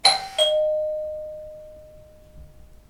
dingdong3
bell door house sound effect free sound royalty free Sound Effects